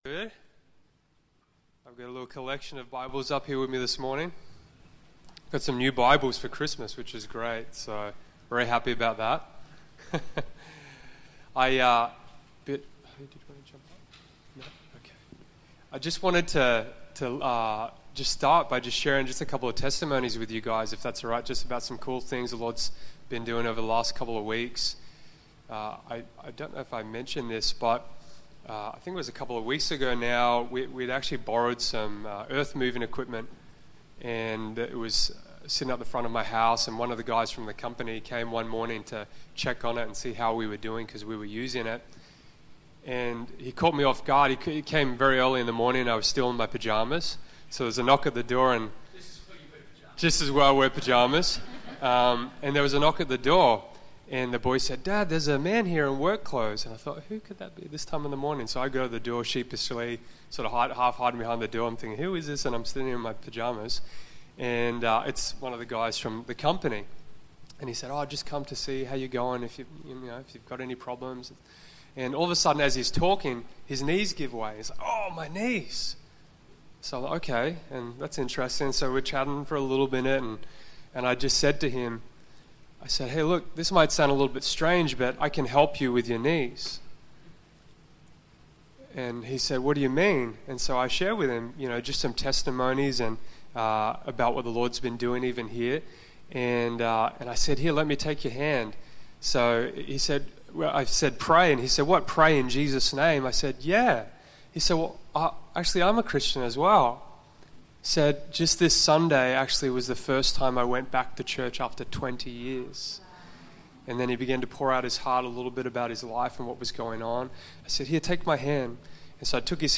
Media Audio Sermons To Listen online; Simply click on a sermon title below to open up the sermon in an audio player.